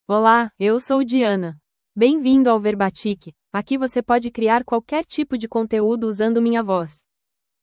Diana — Female Portuguese (Brazil) AI Voice | TTS, Voice Cloning & Video | Verbatik AI
DianaFemale Portuguese AI voice
Diana is a female AI voice for Portuguese (Brazil).
Voice sample
Listen to Diana's female Portuguese voice.
Diana delivers clear pronunciation with authentic Brazil Portuguese intonation, making your content sound professionally produced.